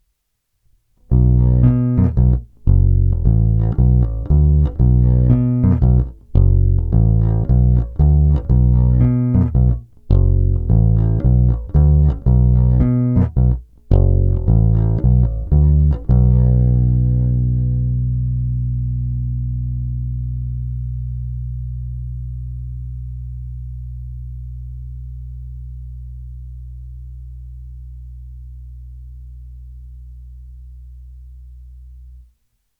Následující ukázky byly pořízeny s nylonovými hlazenými strunami D'Addario ETB92 Tapewound Bass s tloušťkami .050" až .105." Tónové clony byly vždy plně otevřené.
Nahrávky jsou jednotlivě normalizovány, jinak ponechány bez dodatečných úprav.
Krkový snímač
Na krkový snímač to má dosti kontrabasový zvuk, zvlášť když se ještě stáhne tónová clona.